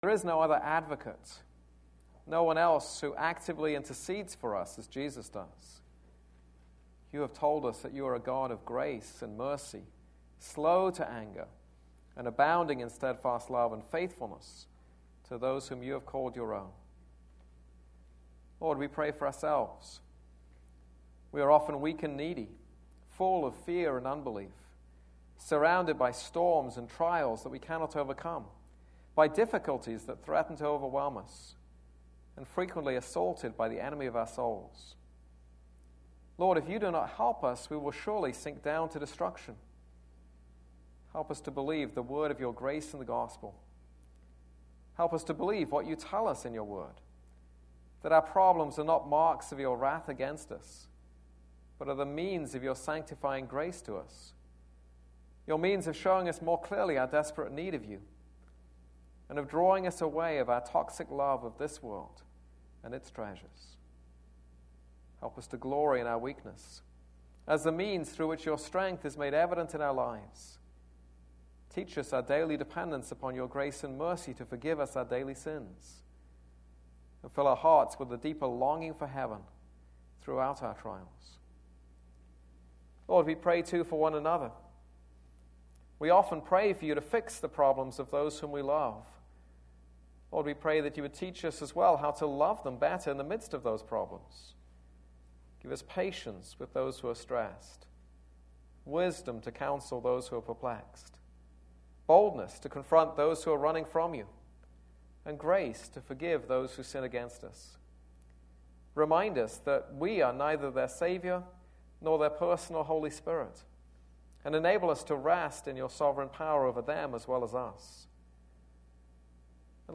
This is a sermon on Song of Songs 6:11-8:4.